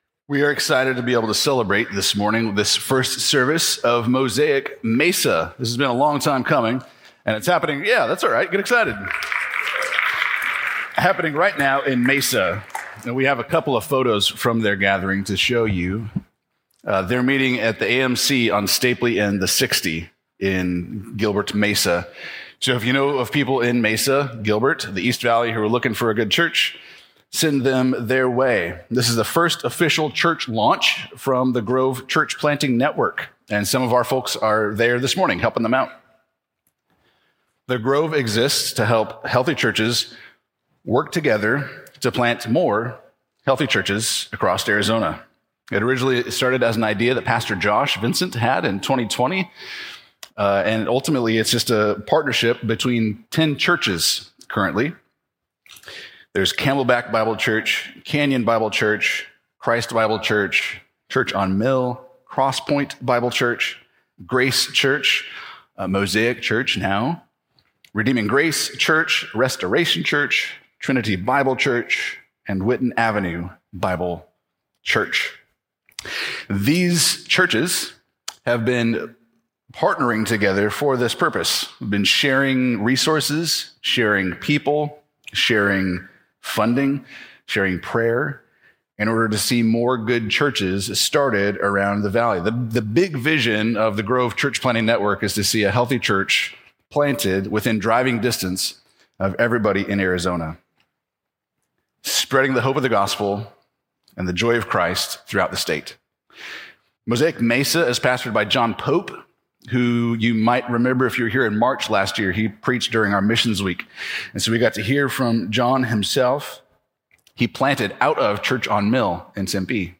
Expositional Preaching from Trinity Bible Church in Phoenix, Arizona